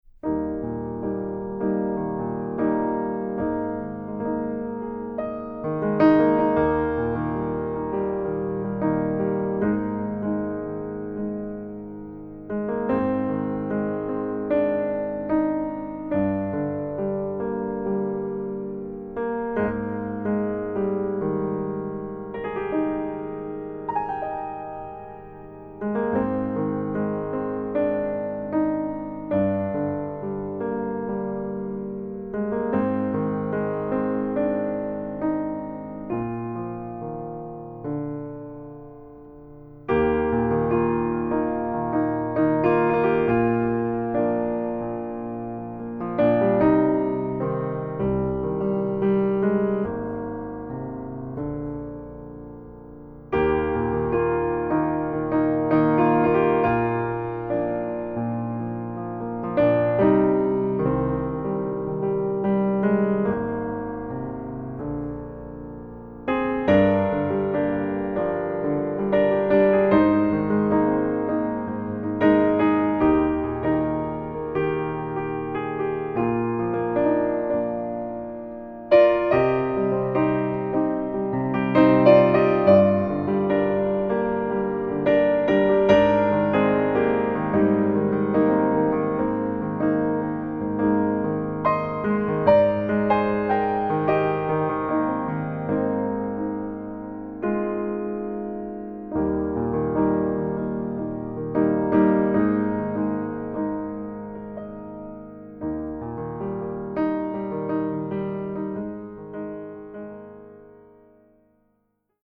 Level : Intermediate | Key : E-flat | Individual PDF : $3.99